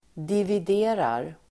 Uttal: [divid'e:rar]